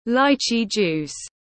Nước ép quả vải tiếng anh gọi là lychee juice, phiên âm tiếng anh đọc là /ˈlaɪ.tʃiː ˌdʒuːs/